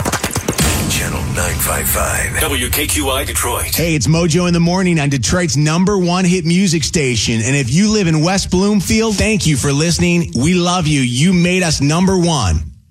Grappig is dat Channel 955 de luisteraars in de uuropener bedankt voor de nummer 1 positie (audio hieronder).